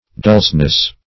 dulceness - definition of dulceness - synonyms, pronunciation, spelling from Free Dictionary Search Result for " dulceness" : The Collaborative International Dictionary of English v.0.48: Dulceness \Dulce"ness\, n. Sweetness.